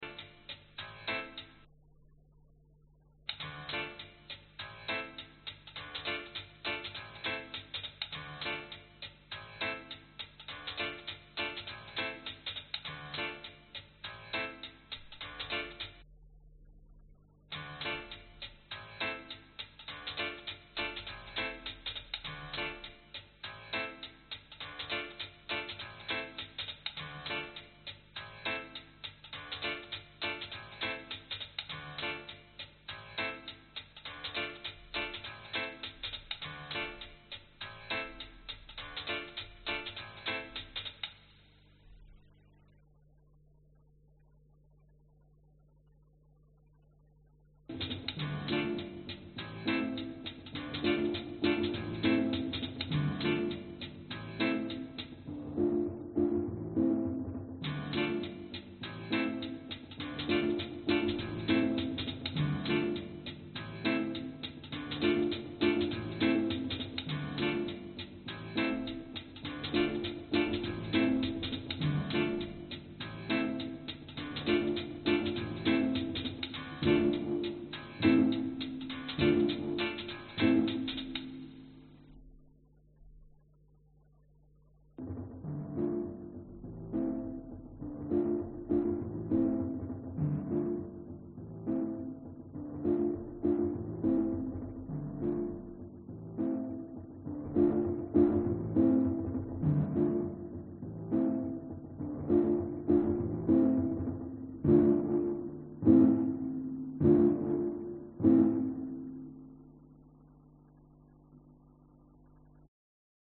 Tag: 英国委员会 合作 跨文化 嘻哈 跨文化 猕猴桃 音乐 新西兰 人在你身边 雷鬼